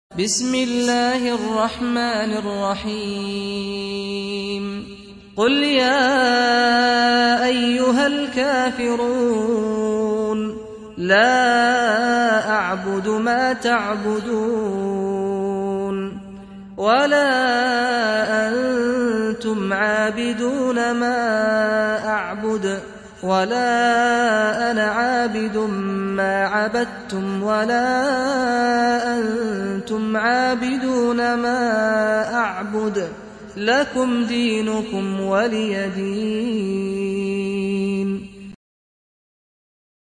Audio Quran Tarteel Recitation
Surah Repeating تكرار السورة Download Surah حمّل السورة Reciting Murattalah Audio for 109. Surah Al-K�fir�n سورة الكافرون N.B *Surah Includes Al-Basmalah Reciters Sequents تتابع التلاوات Reciters Repeats تكرار التلاوات